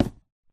wood3.ogg